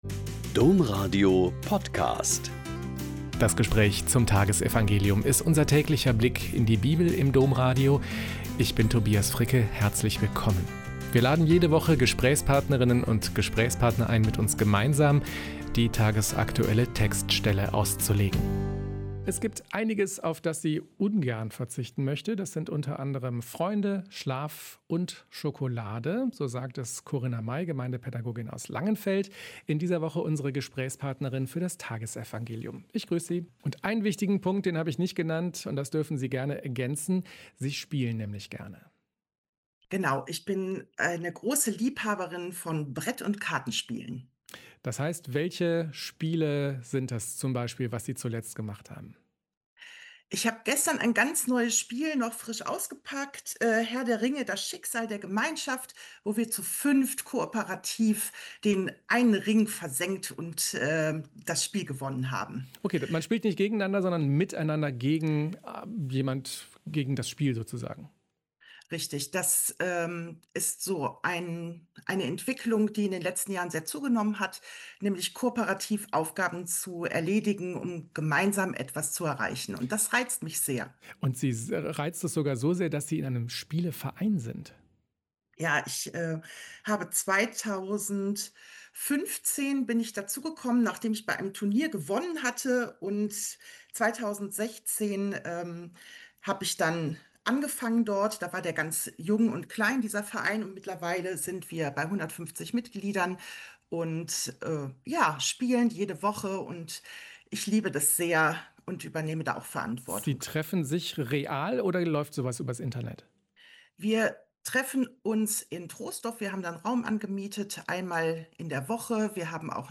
Mk 6,17-29 - Gespräch